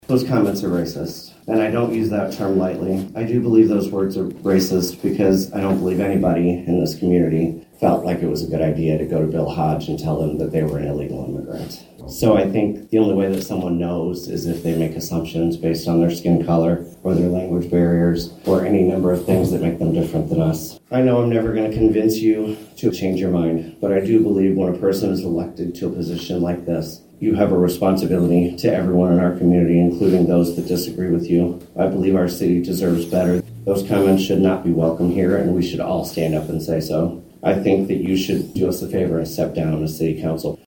Monday night, during public comment of the Hampton City Council budget workshop meeting, some residents voiced their concerns about councilmember Bill Hodge and a remark he made during the February 3rd meeting about ICE making arrests in the area.